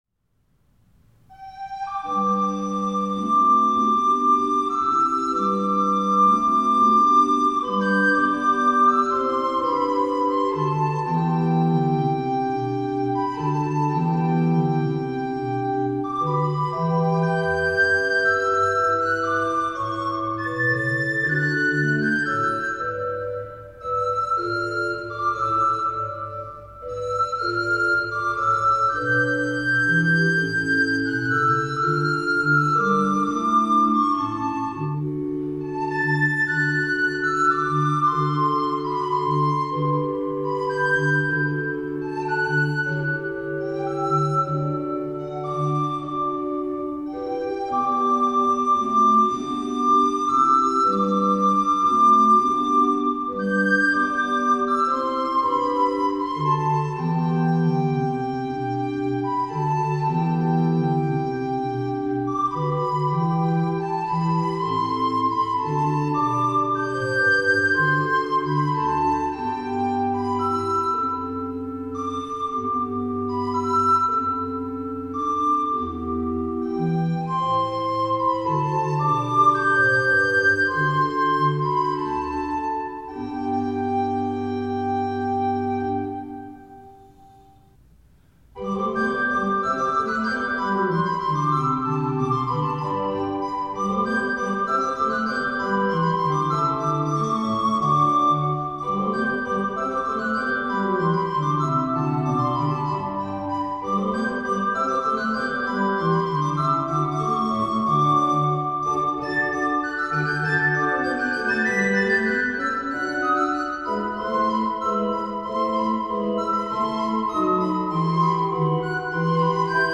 Siciliana-Allegro-Allegro-Vivace
Flöte
Orgel
Telemann-Partita-in-G-Dur-Flöte-und-Orgel.mp3